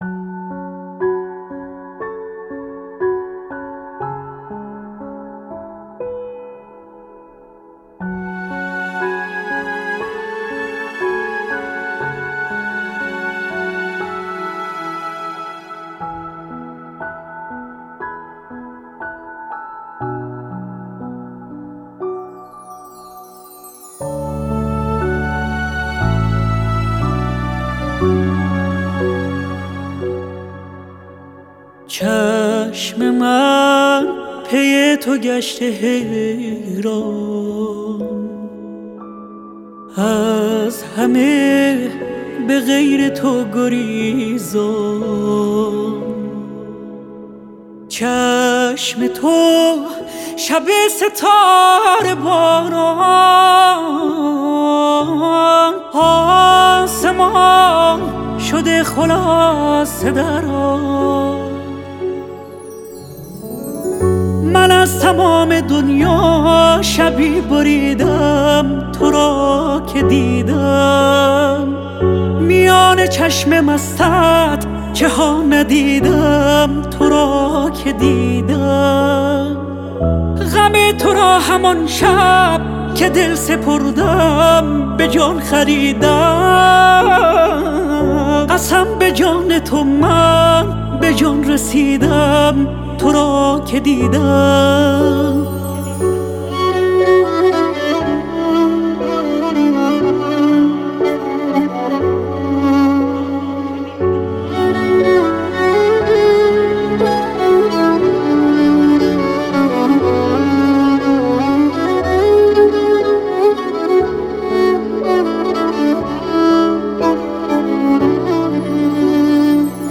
دانلود اجرای زنده
LIVE IN CONCERT